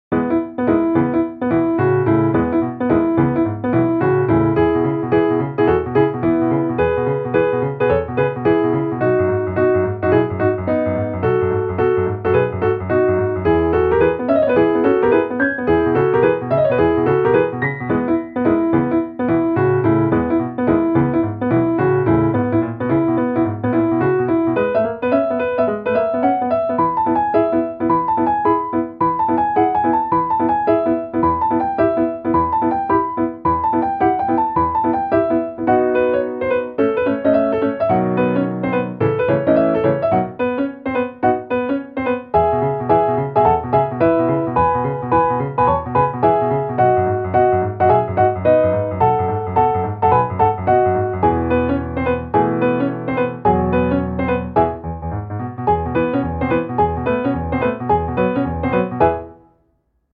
• コミカルや気軽に聞けるピアノ曲のフリー音源を公開しています。
ogg(L) - お洒落 軽やか リズミカル